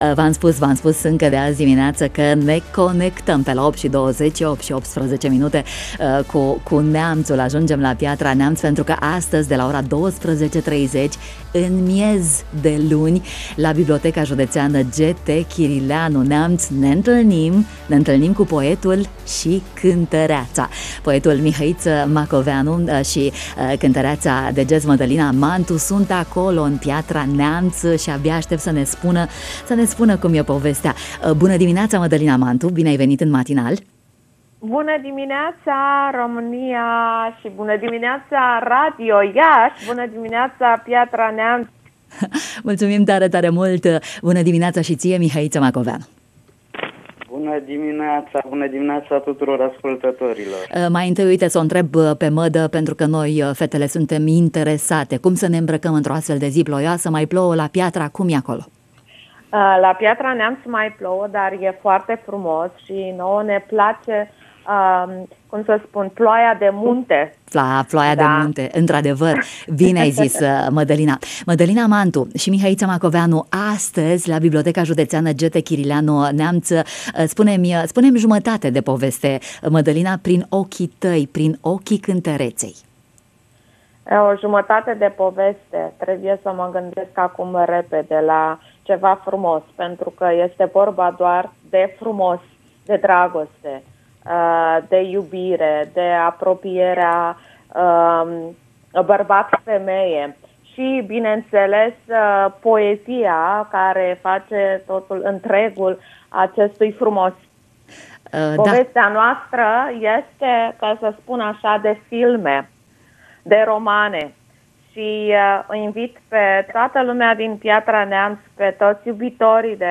la microfonul Radio România Iaşi: